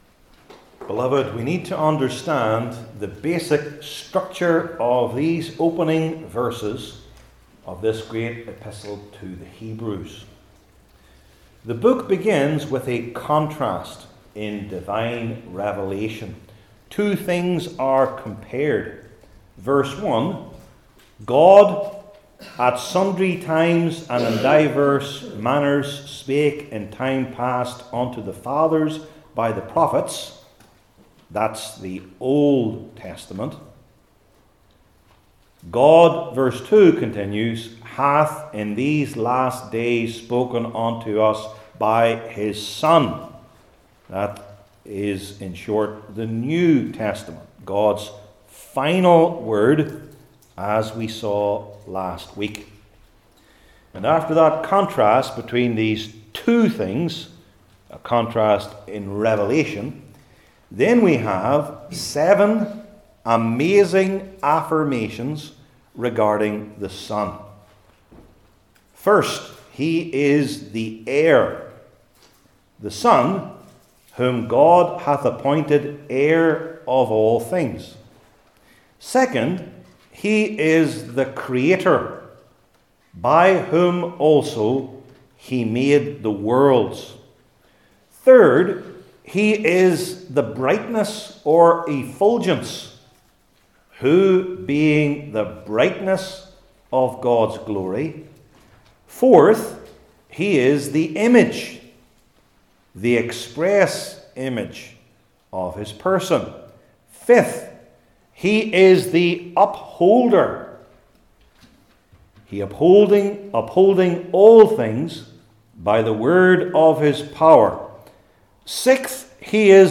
Hebrews 1:2-3 Service Type: New Testament Sermon Series I. The Meaning II.